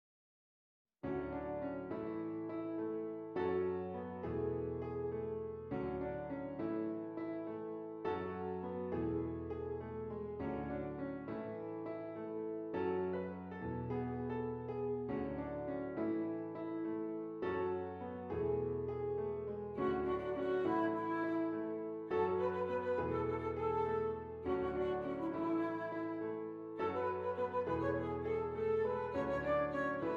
Flute Solo with Piano Accompaniment
A Major
Moderate